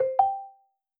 spoken_feedback_enabled.wav